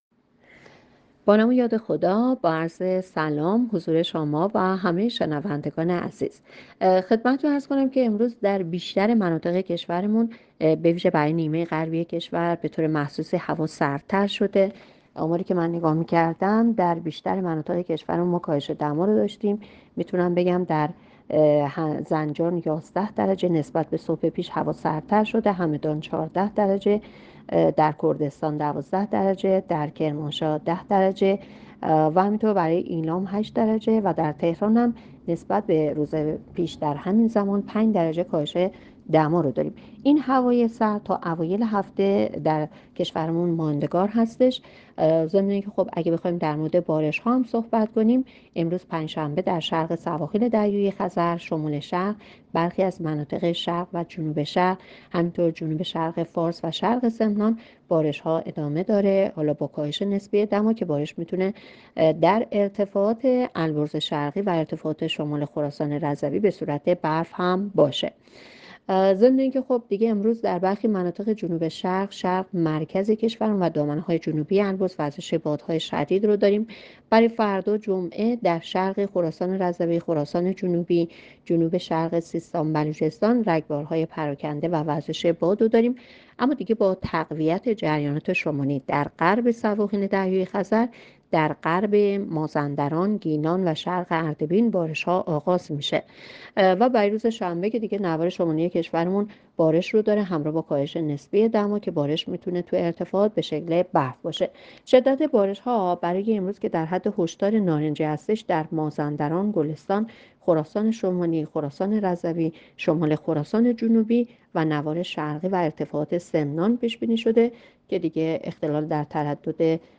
گزارش رادیو اینترنتی پایگاه‌ خبری از آخرین وضعیت آب‌وهوای ۸ آذر؛